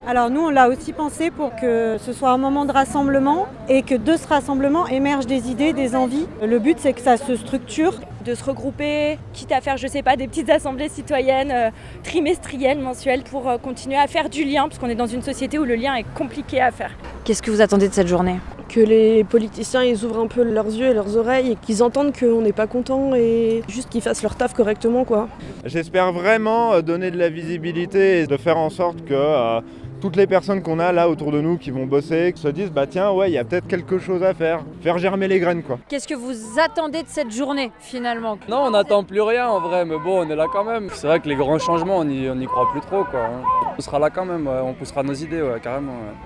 Une quarantaine de personnes étaient rassemblées mercredi 10 septembre au rond-point de l’Europe.
Malgré ce nouveau changement de chef du gouvernement les manifestants restent contrariés et ils expliquent ce qu’ils attendaient de cette journée de mobilisation du mercredi 10 septembre 2025.